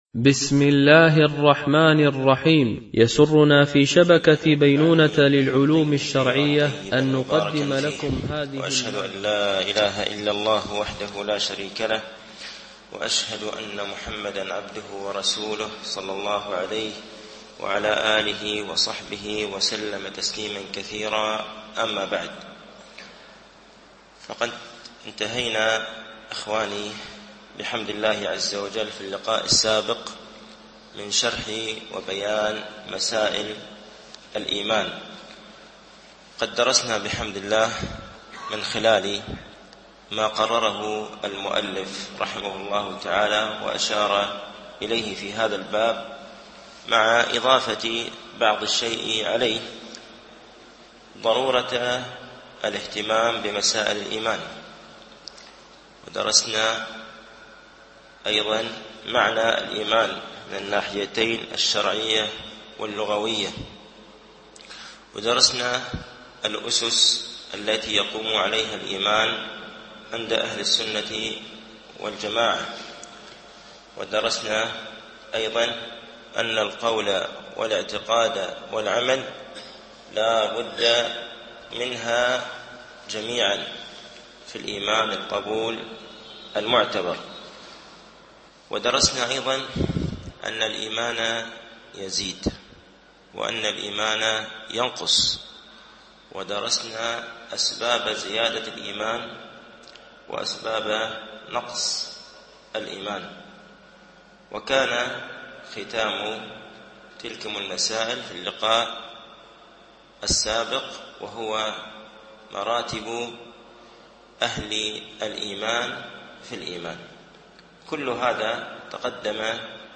شرح مقدمة ابن أبي زيد القيرواني ـ الدرس الخامس و الستون